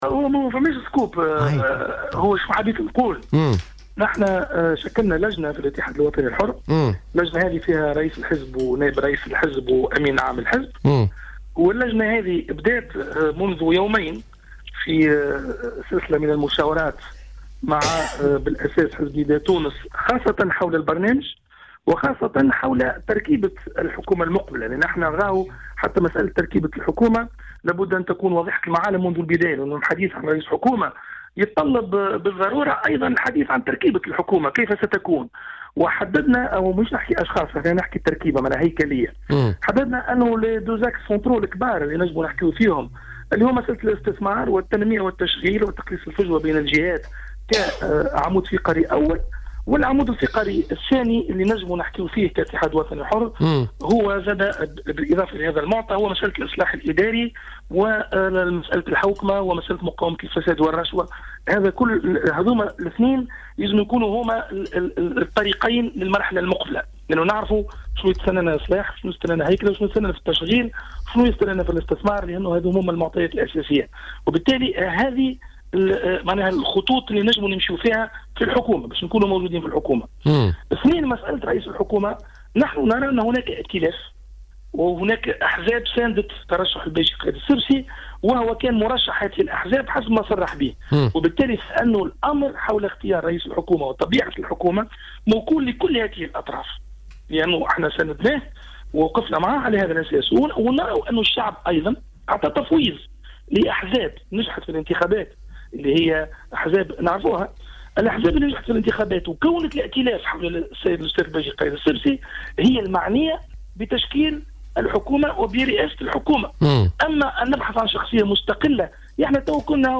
a tenu à rappeler vendredi lors de son intervention sur les ondes de Jawhara Fm que seuls Nidaa Tounes et les partis vainqueurs aux élections législatives sont concernés par la désignation du nouveau chef du gouvernement.